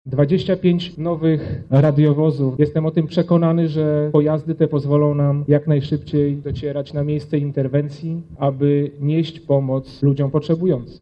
Podczas uroczystości Minister Spraw Wewnętrznych przekazał również klucze do nowych radiowozów.
Każdy funkcjonariusz lubelskiej policji dokłada wszelkich starań, aby podnosić poziom bezpieczeństwa – mówi Inspektor Michał Domaradzki, Lubelski Komendant Wojewódzkiej Policji